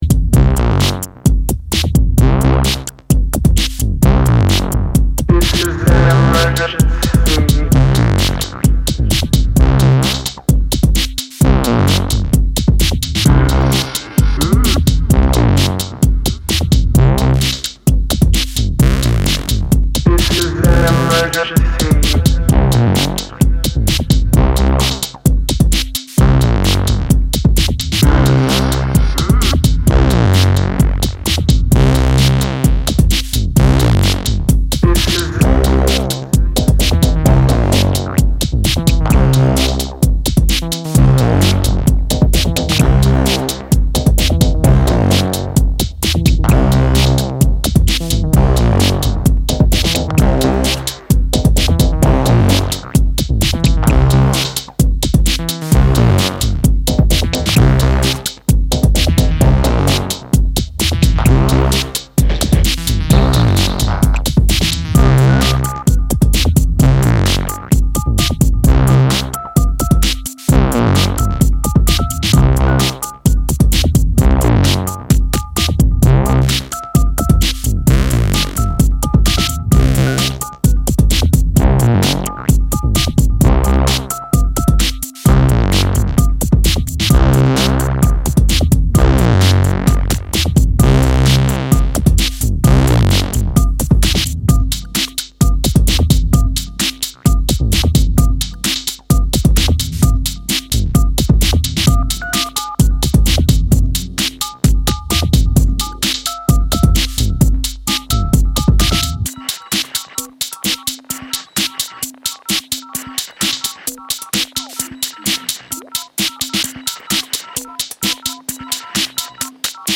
twists things up with a pulsating bass heavy Electro remix